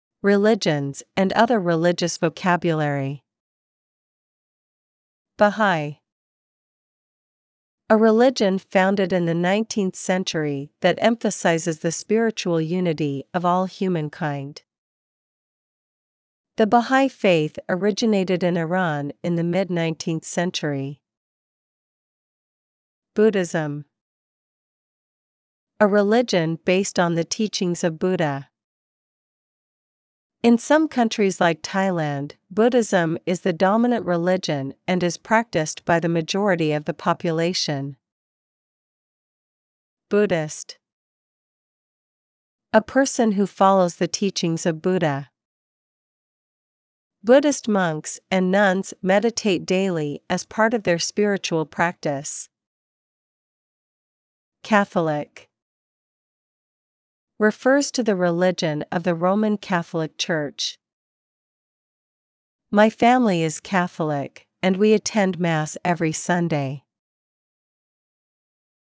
Listen & Learn: Countries of the World: Audiobook 1: Argentina to United States of America